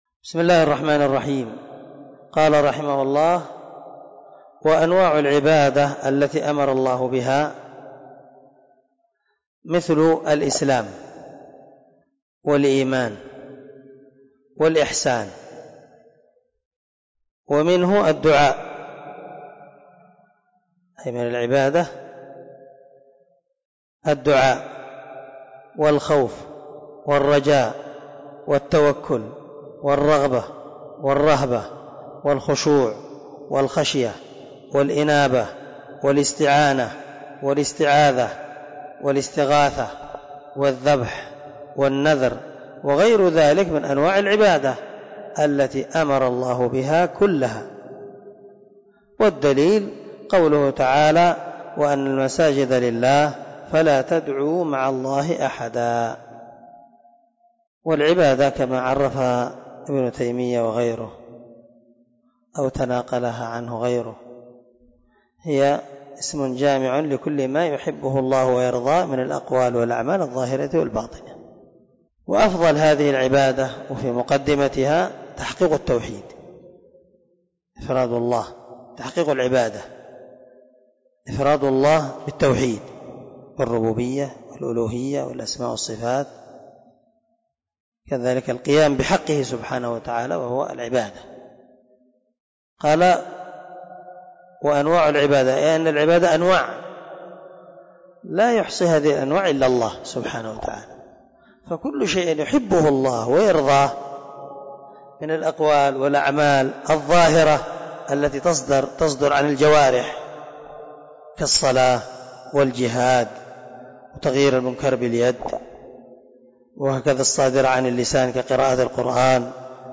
🔊 الدرس 11 من شرح الأصول الثلاثة
الدرس-11.mp3